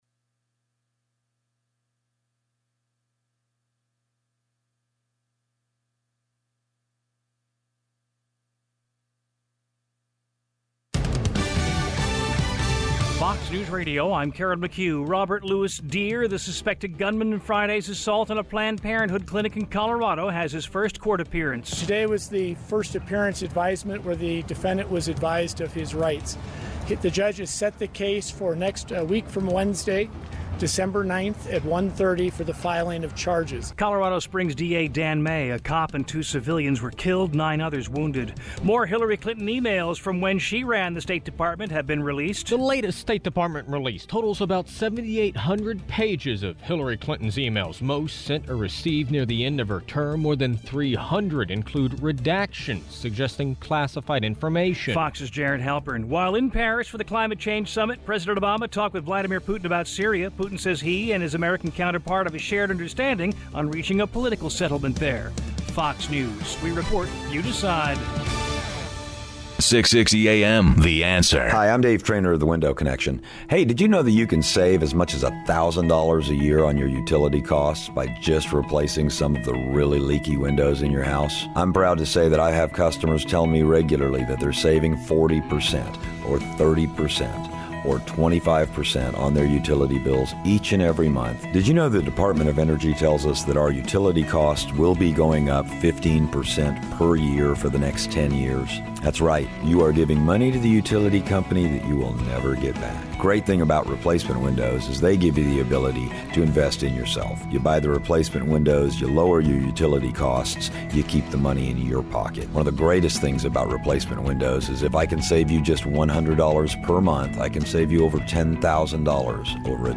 More on Climate Change; Interview with TPPF’s Kathleen Hartnett White